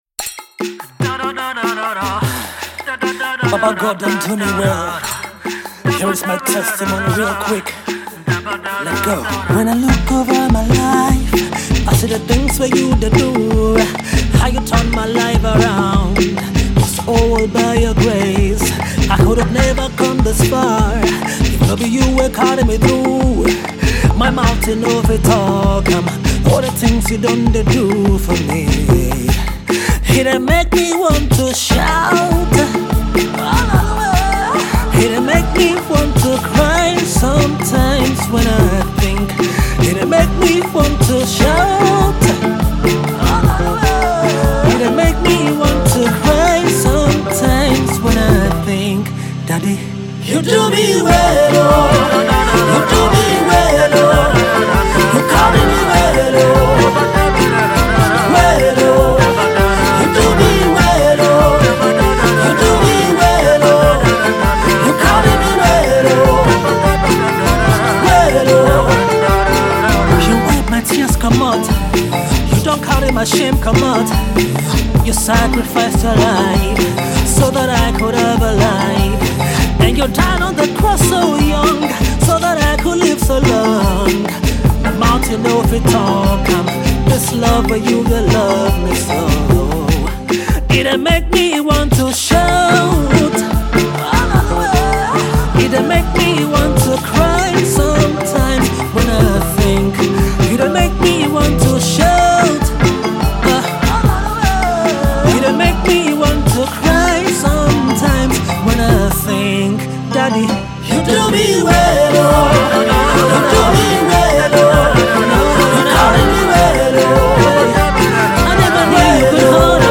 The Swagnified Singer
It is “village music” like you’ve never heard it…